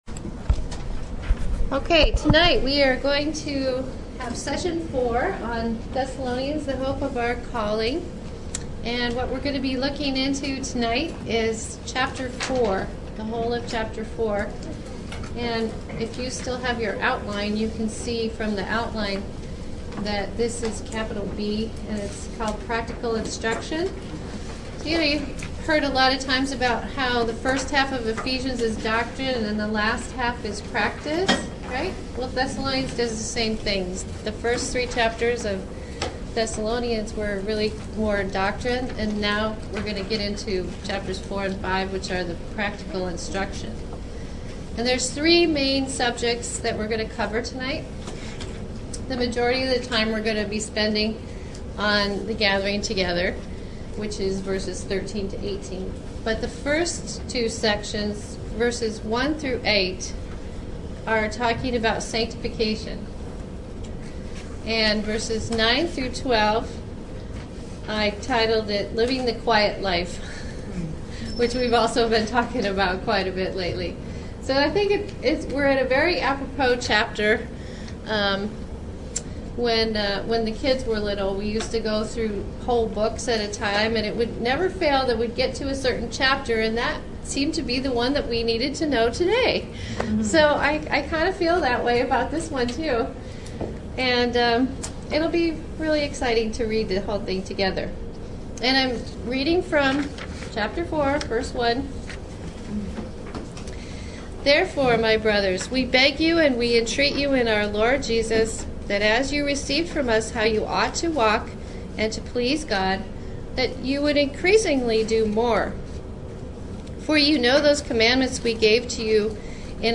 Explore the Thessalonians Audio Teaching Series, Part 4, and learn about sanctification, living the quiet life, and the gathering together.